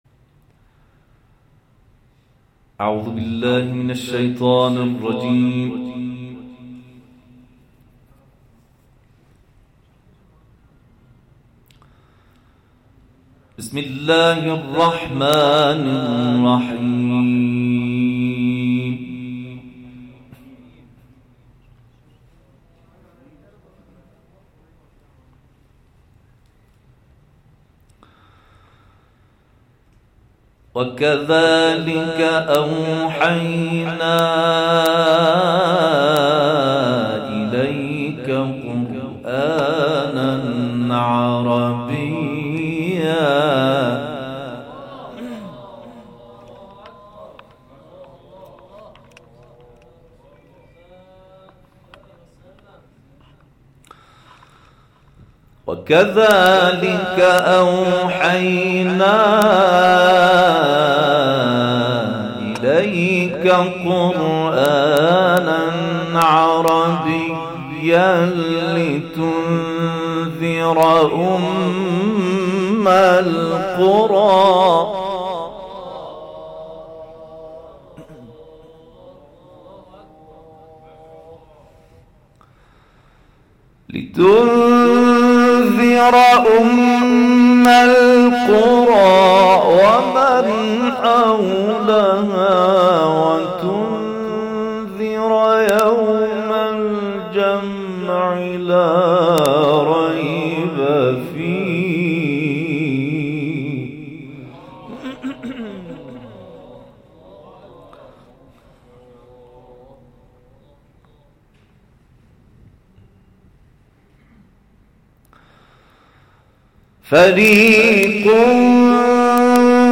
تلاوت سوره شوری